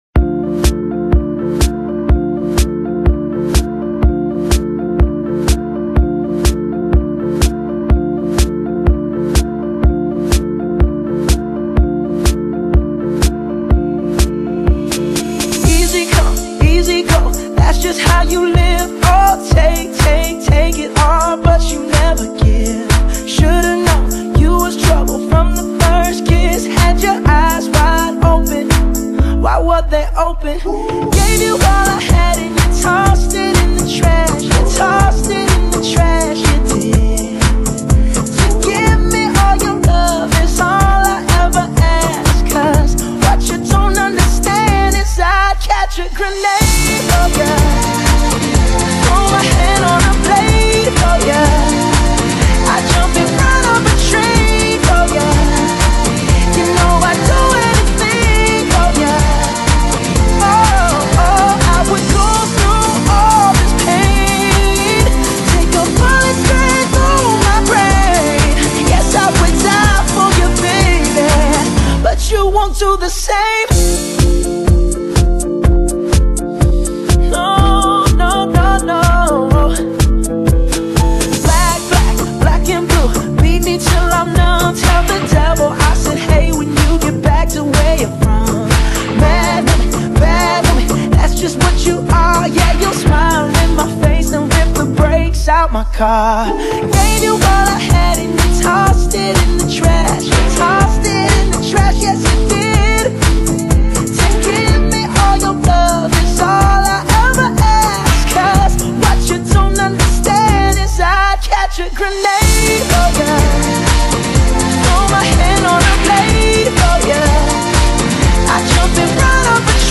Genre: Dance, Pop | 20 Tracks | MU